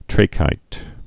(trākīt, trăkīt)